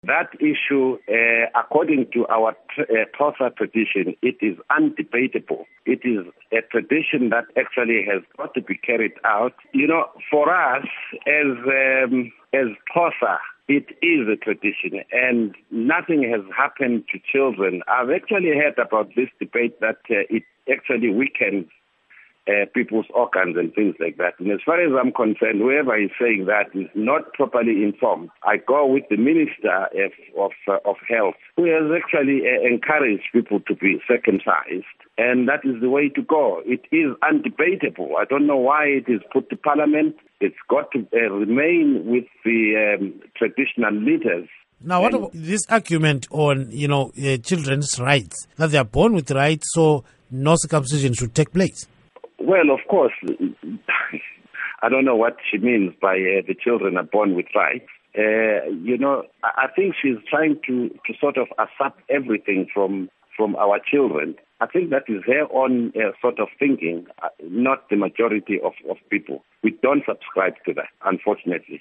Interview With Xhosa Prince McLeod Tshawe